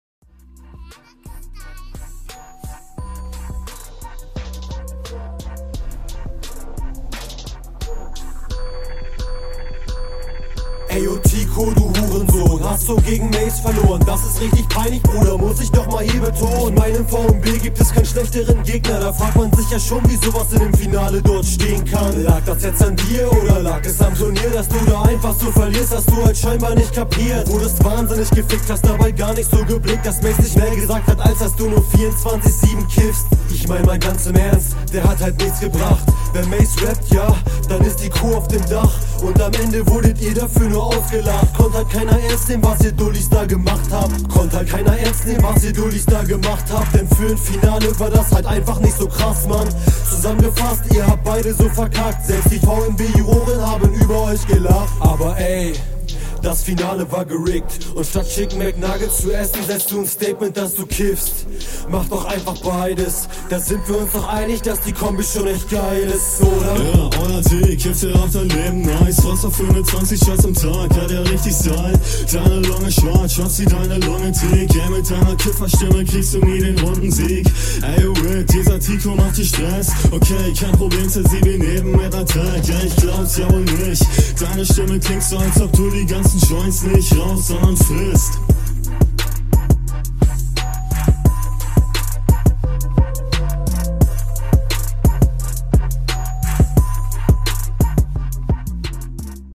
hall effekt kommt nice kann man gut hören ,flow ist chillig ...punkt geht diese runde …